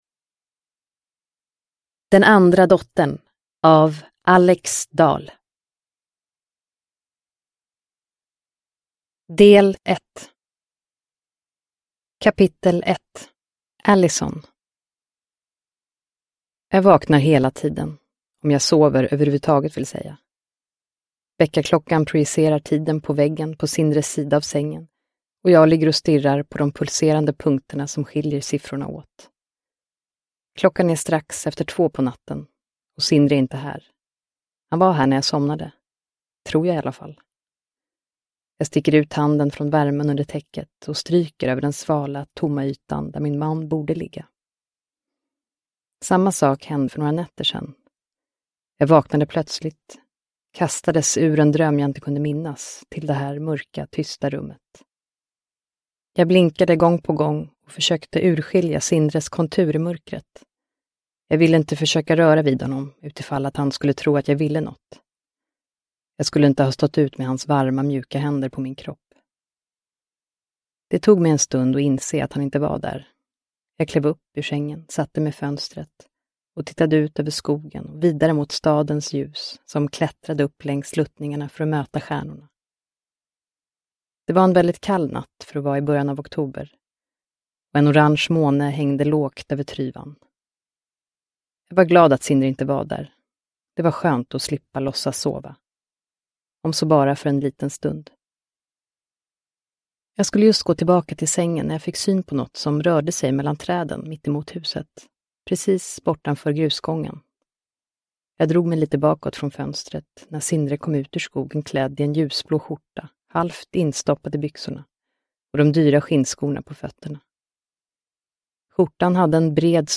Den andra dottern – Ljudbok – Laddas ner